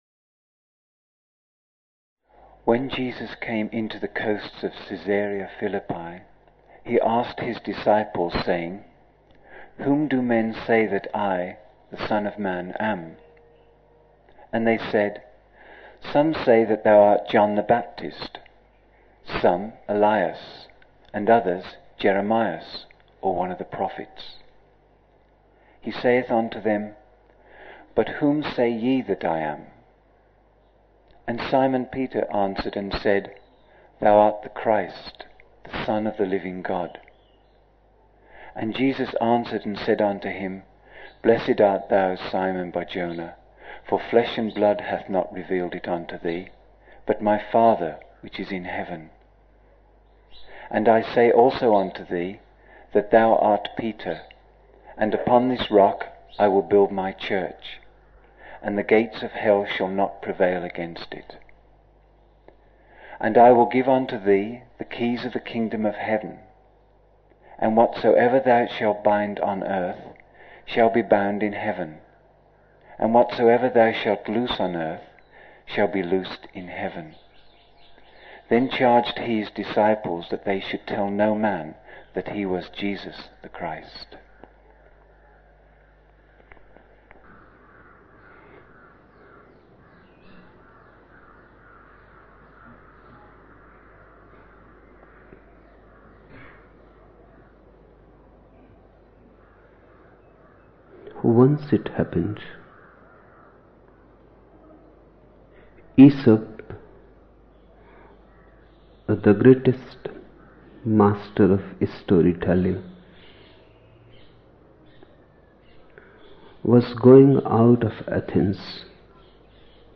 13 December 1975 morning in Buddha Hall, Poona, India